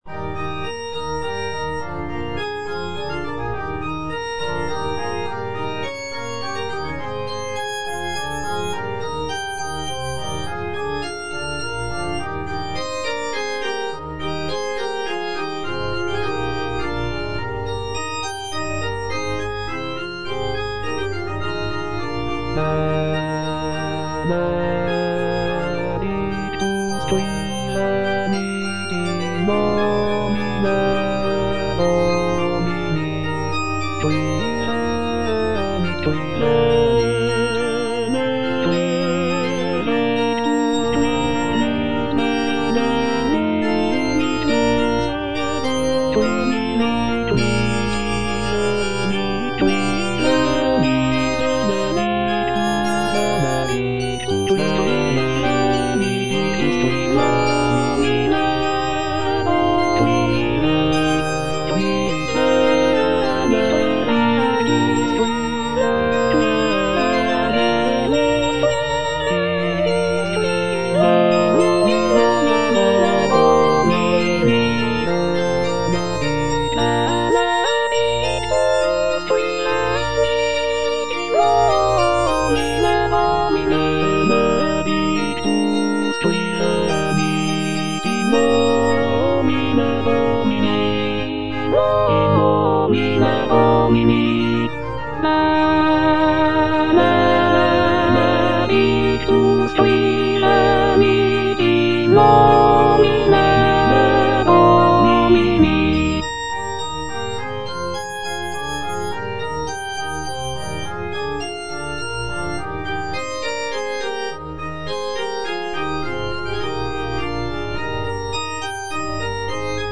Soprano (Emphasised voice and other voices) Ads stop
choral composition